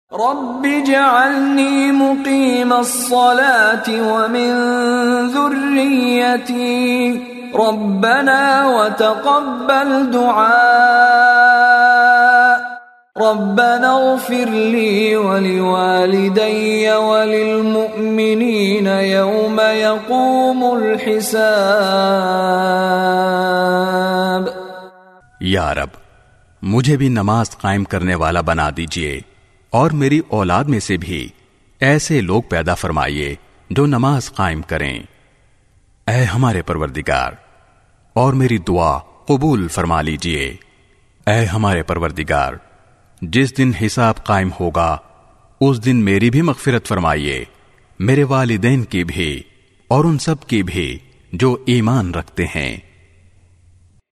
by Mishary Rashid Alafasy
His melodious voice and impeccable tajweed are perfect for any student of Quran looking to learn the correct recitation of the holy book.
rabbi jalni muqeemas salati full dua with translation audio.mp3